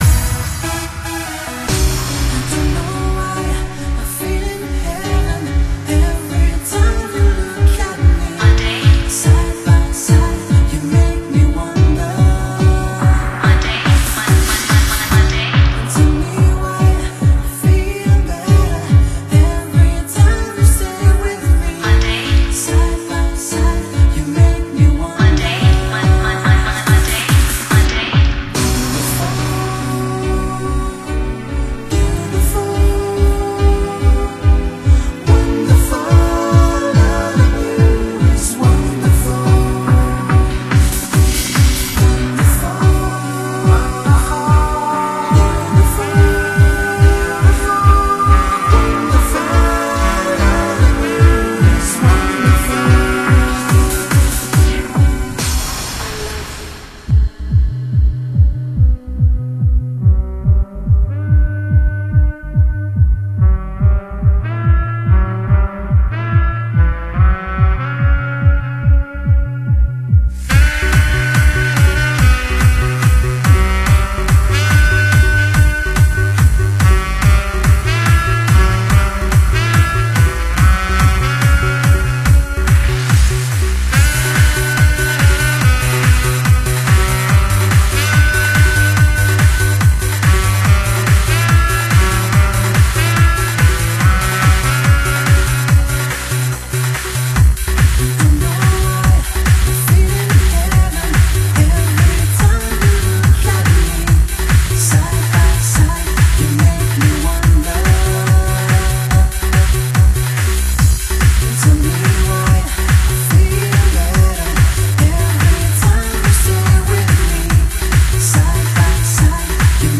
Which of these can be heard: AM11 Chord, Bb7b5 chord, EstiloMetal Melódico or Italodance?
Italodance